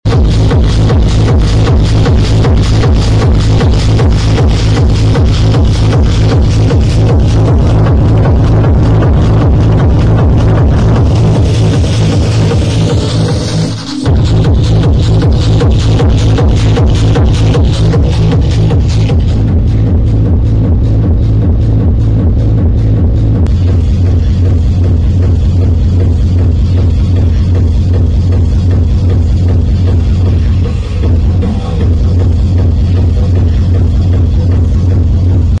Doble 15" jb system, con etapa de 700w 4ohm STA 1400 al 100% asomando clipping va muy justa para estos altavoces empieza a distorsionar